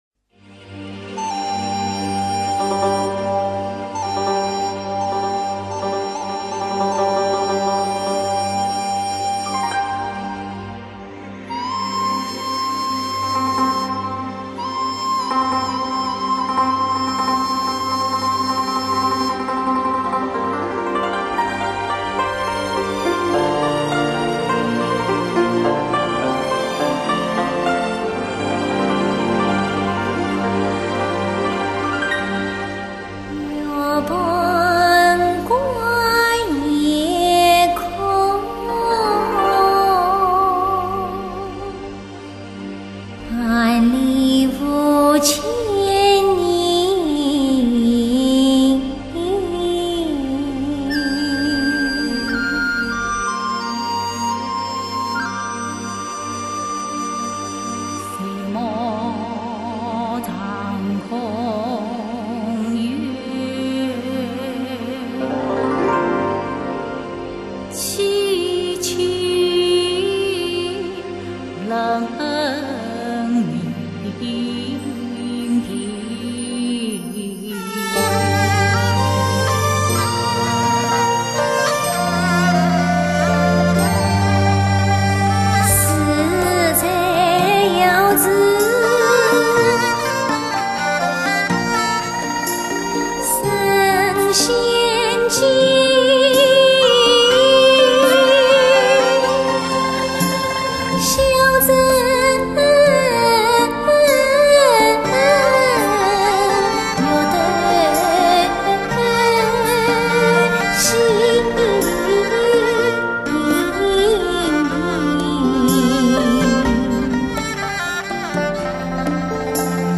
越剧傅派